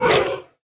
sounds / mob / panda / bite3.ogg
bite3.ogg